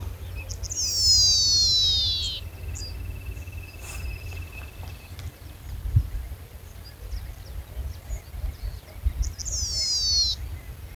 Yellowish Pipit (Anthus chii)
Country: Argentina
Location or protected area: Mburucuyá (localidad)
Condition: Wild
Certainty: Photographed, Recorded vocal